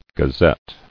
[ga·zette]